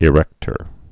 (ĭ-rĕktər)